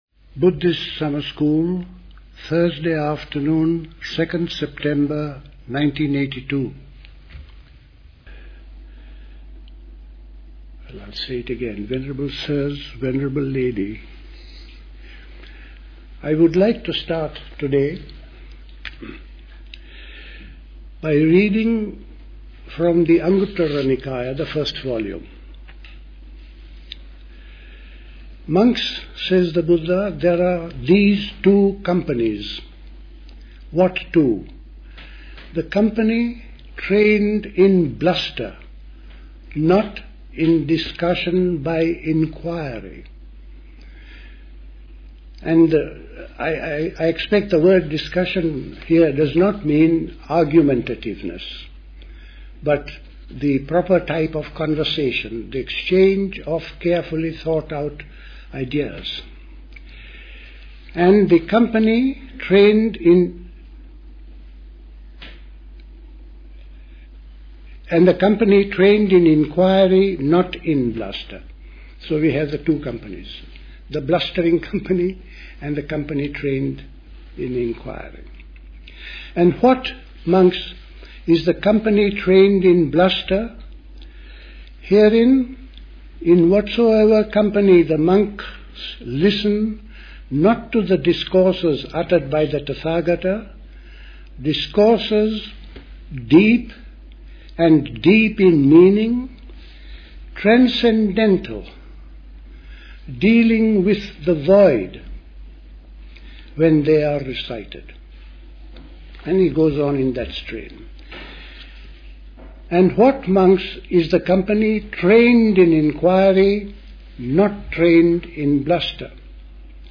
Talk
The Buddhist Society Summer School